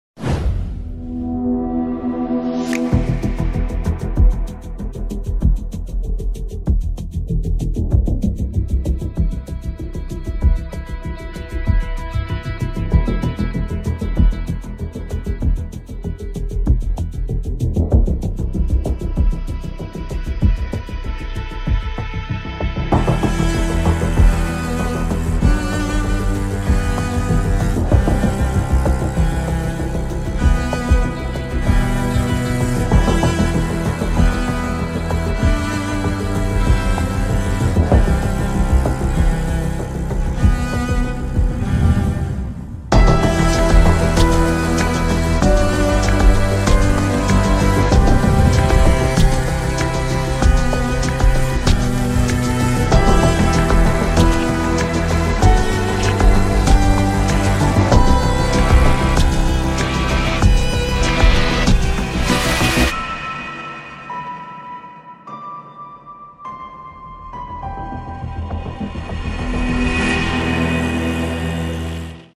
title sequence
An original score by ME!